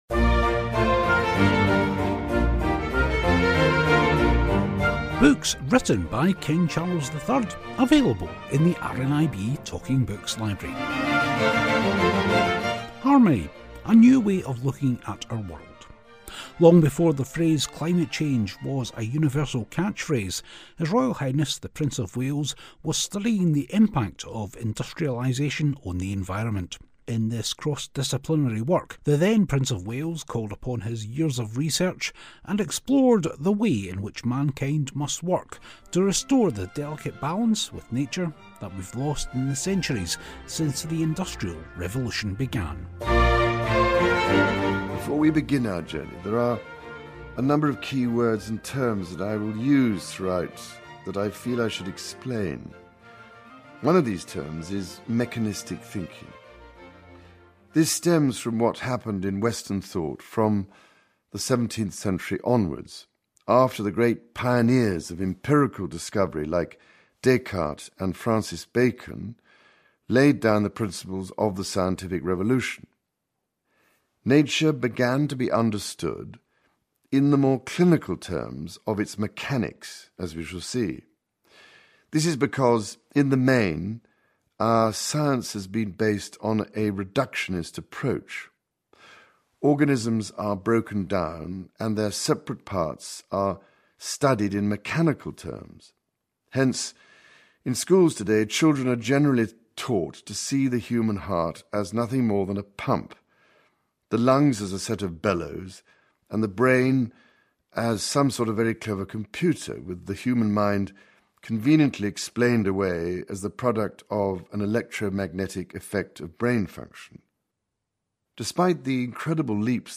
Today he's reading some books by royal authors, including King Charles himself.